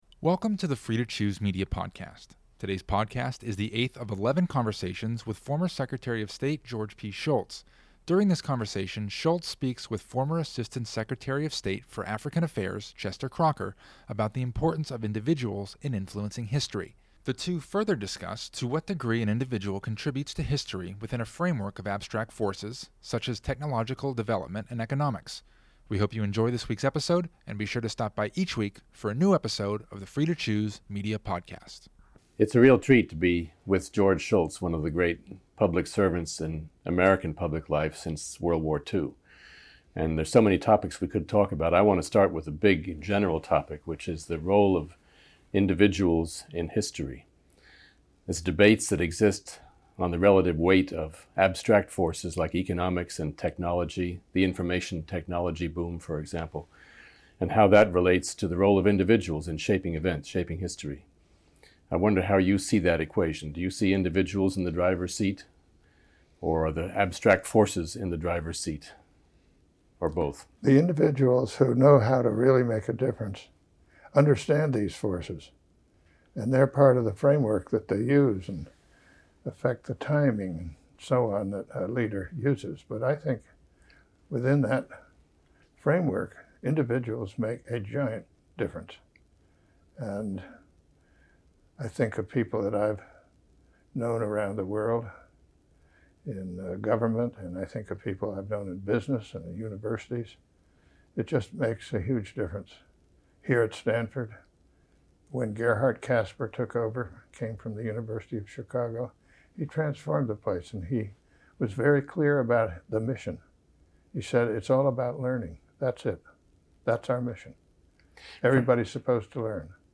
This podcast, the eighth of eleven conversations with former Secretary of State George P. Shultz, features Shultz and former Assistant Secretary of State for African Affairs Chester Crocker. Originally recorded in 2006, the conversation centers around the importance of individuals in influencing history. The two discuss what an individual can contribute within a framework of abstract forces such as technological development and economics.